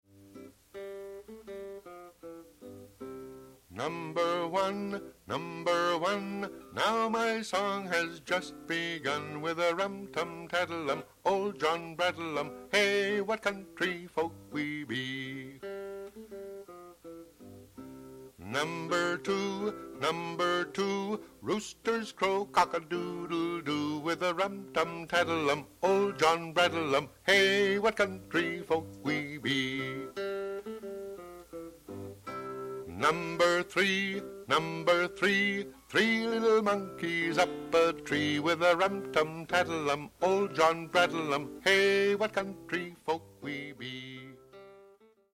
アメリカのフォーク・ミュージックには、子どものための歌を作る伝統があります。
数え歌やアルファベットの歌